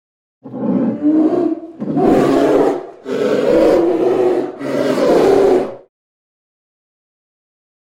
На этой странице собраны звуки полярного медведя – мощные рыки, ворчание и шаги по снегу.
Он постоянно рычит